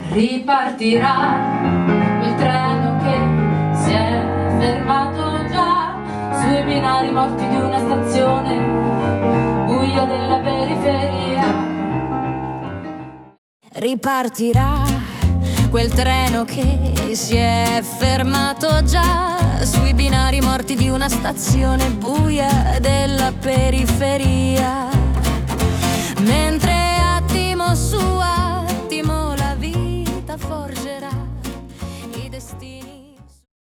From live demo → AI arrangement + vocals.
• Start: original (live)
• 00:13: AI arrangement + vocals